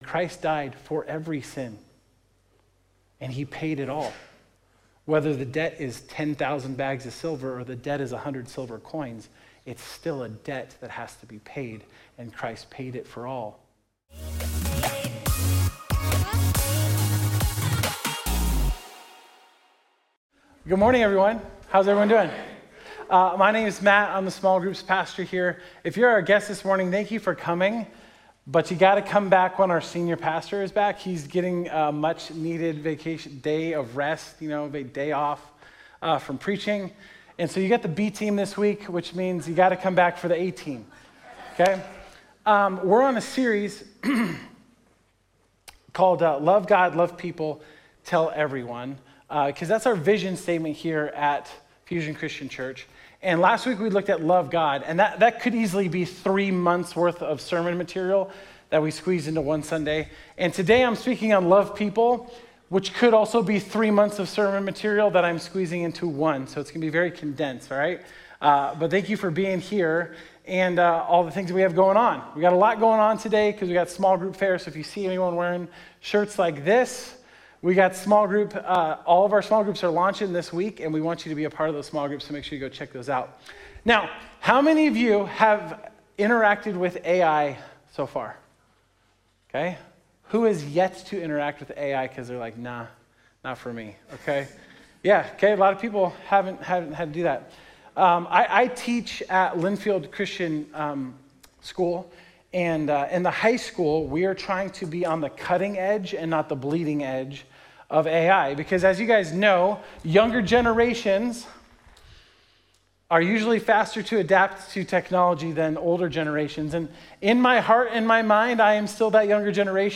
Community Love Sunday Morning At Fusion Christian Church, Loving others is a major part of our church vision.